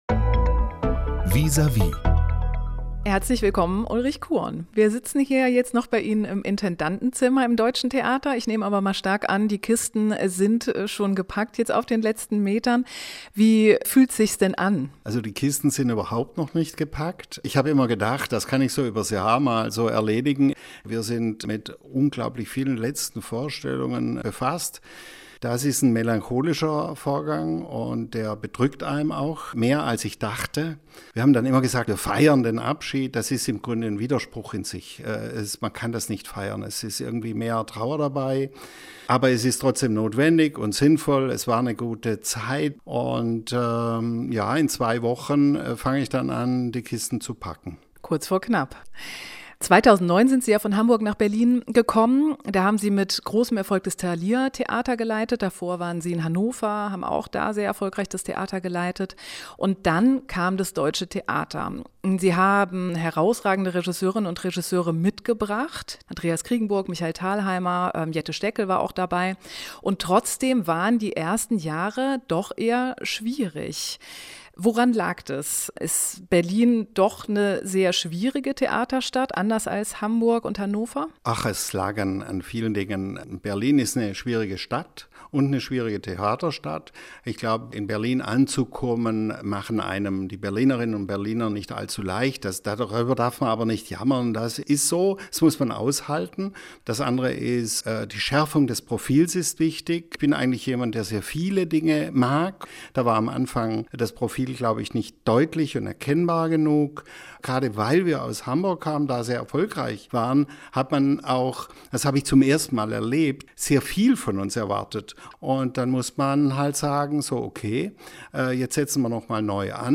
Beim Interview sitzt Khuon noch im Intendantenzimmer des Deutschen Theaters.